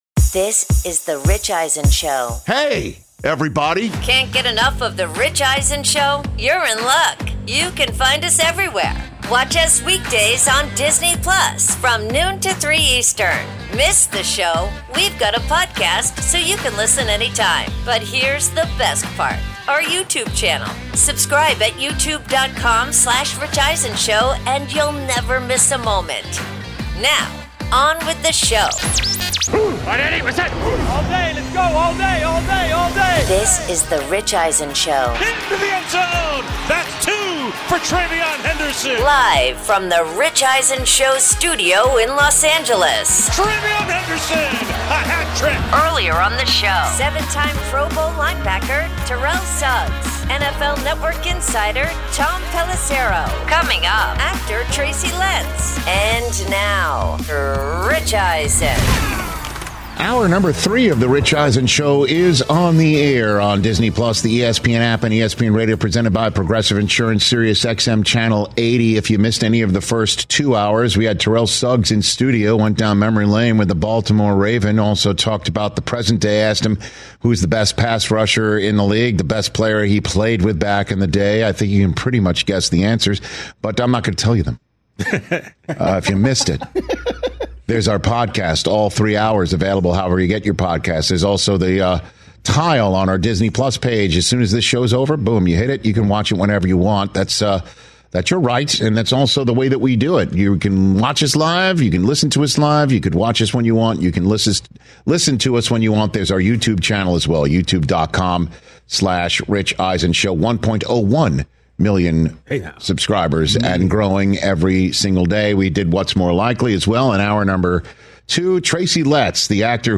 Hour 3: NFL Week 11’s Top 5 Games, NL & AL MVP Reactions, plus actor Tracy Letts In-Studio